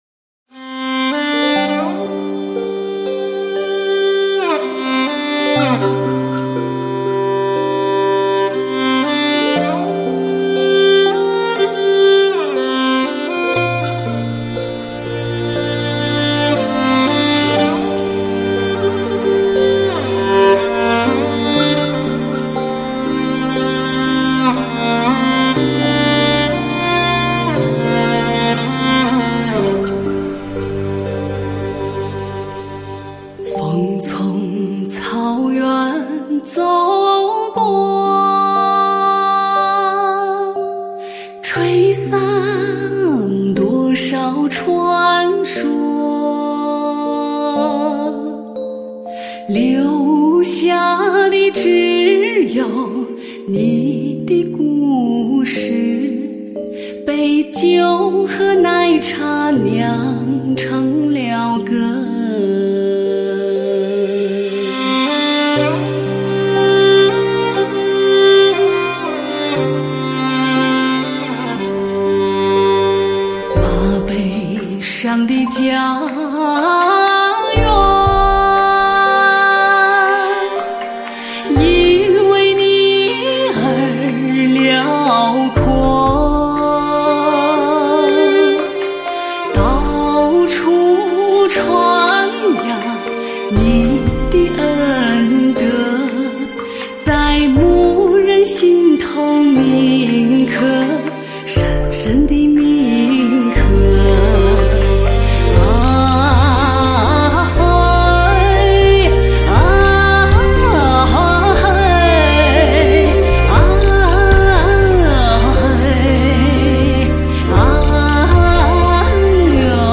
专辑格式：DTS-CD-5.1声道
来自天籁之音的传说 汇聚具灵气的原生态曲风